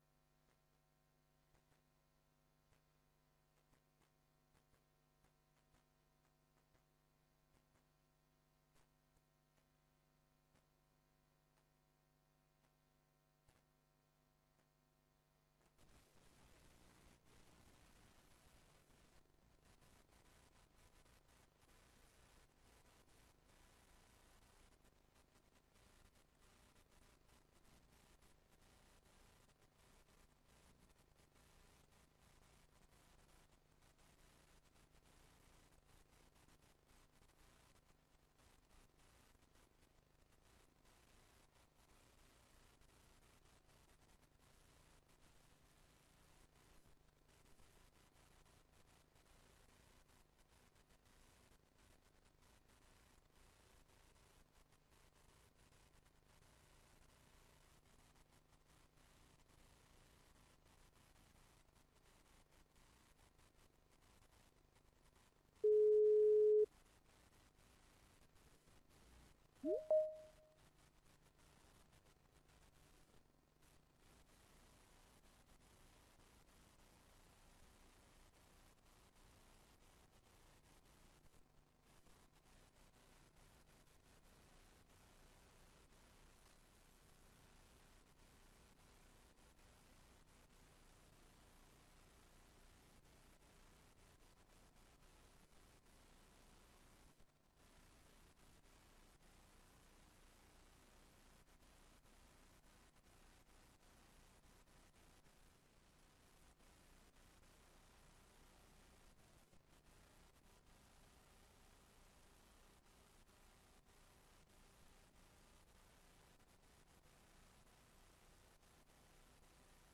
Locatie: Raadszaal